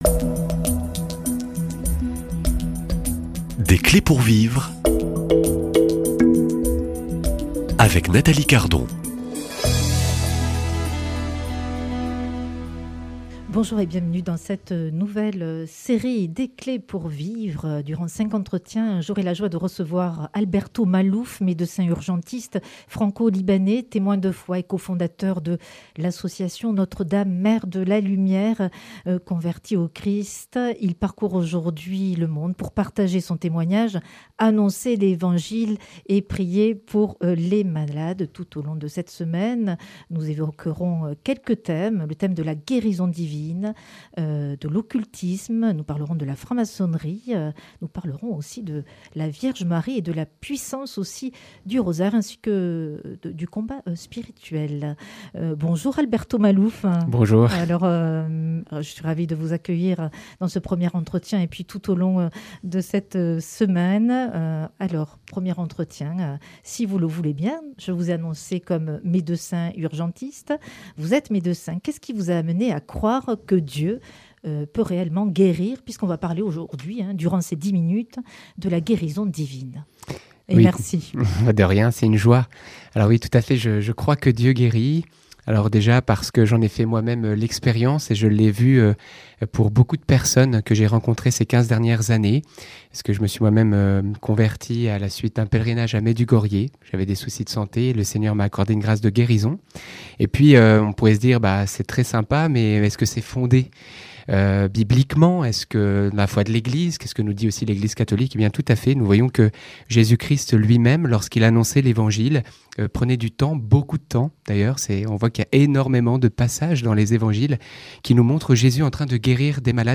Tout au long de ces entretiens, nous aborderons quelques thèmes : la guérison divine, l’occultisme, la pureté, l’adultère, le combat spirituel et Marie et la puissance de la prière du rosaire.